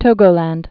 (tōgō-lănd)